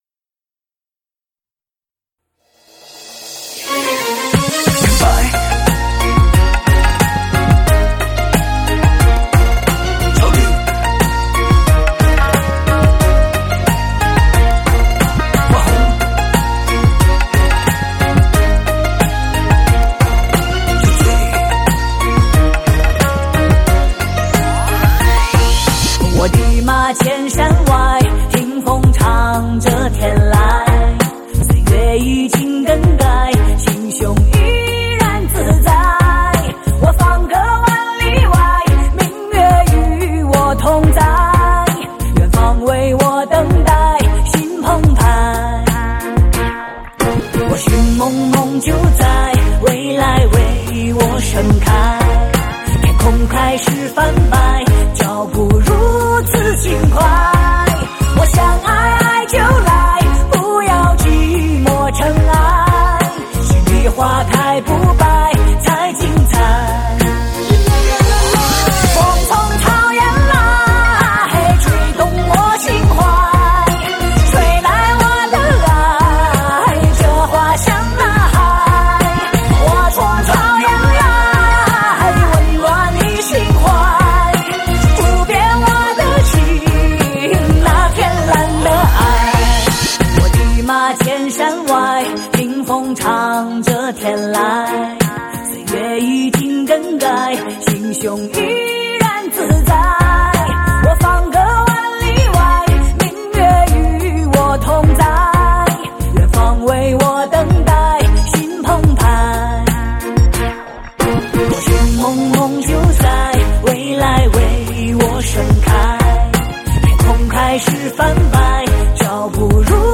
年度引领潮流风尚的畅销女声发烧大碟，汇集2010-2011中国流行乐坛最热金曲。